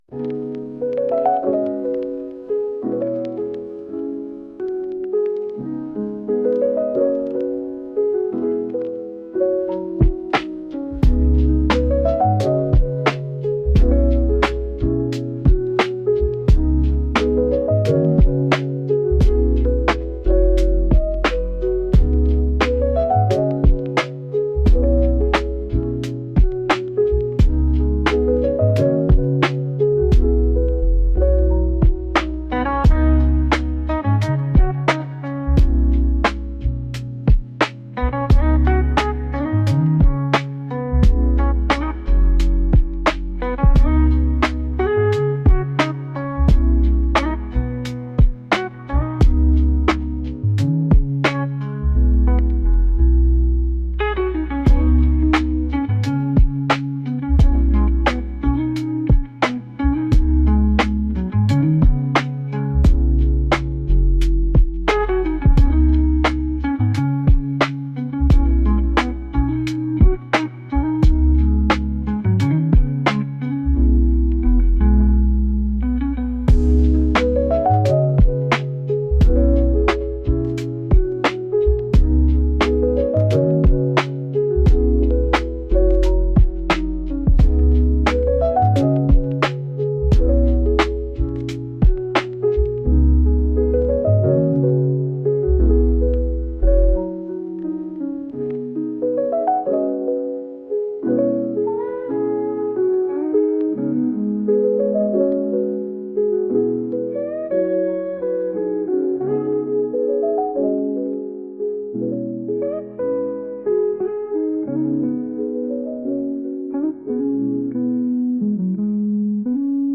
ゆったりしたヒーリング曲のようなピアノ曲です。